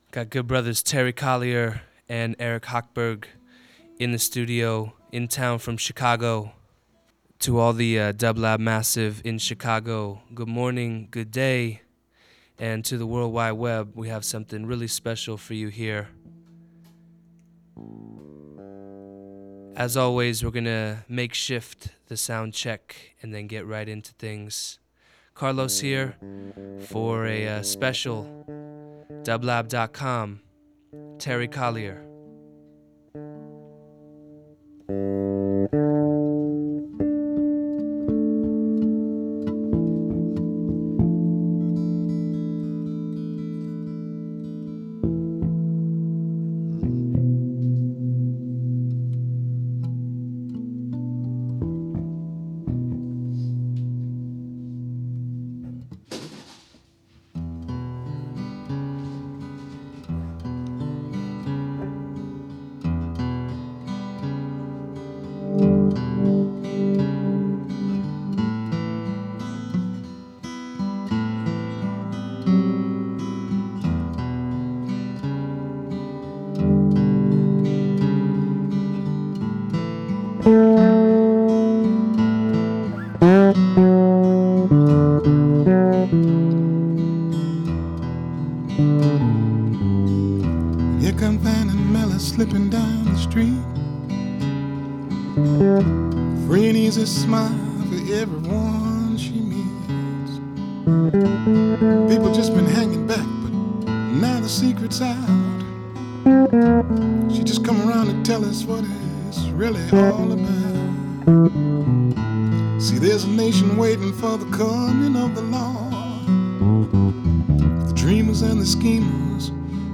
An interview with the guitarist/singer-songwriter follows.
Folk Funk/Soul Jazz